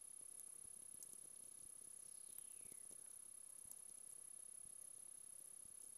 Forest, Virginia